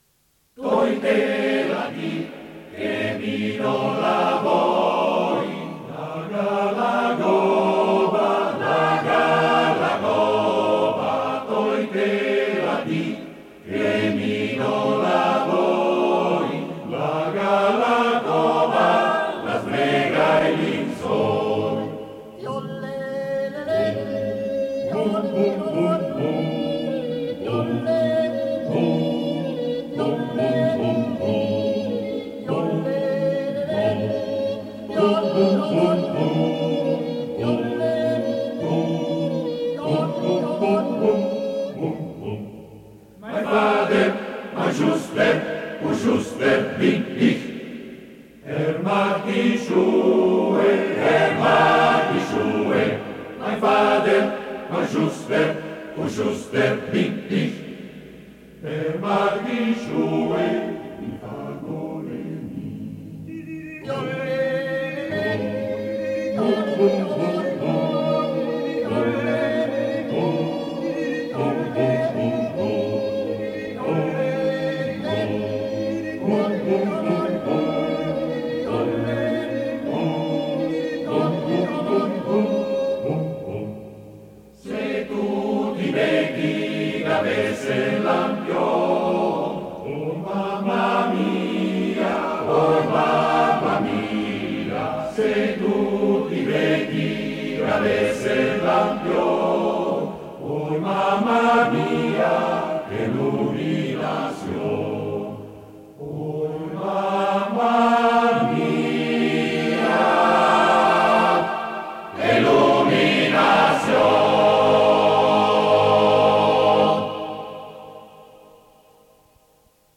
Esecutore: Coro Monte Cauriol